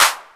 Clap (RUN).wav